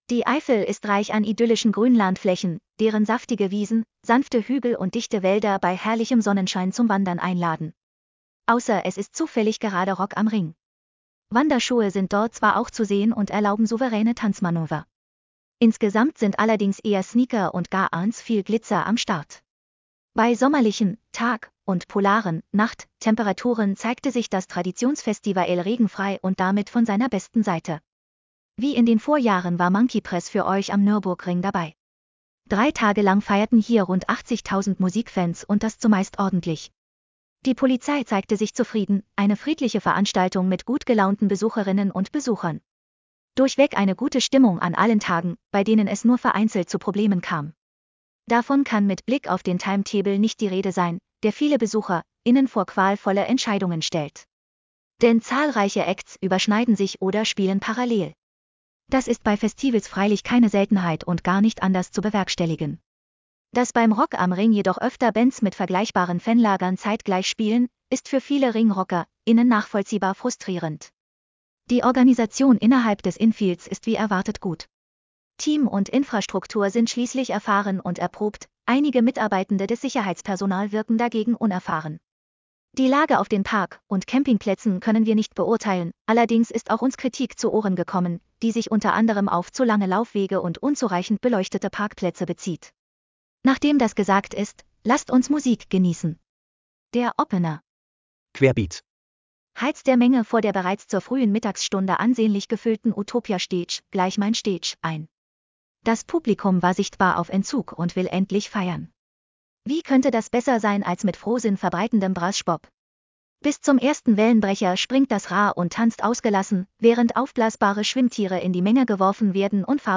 Lass Dir den Beitrag vorlesen: /wp-content/T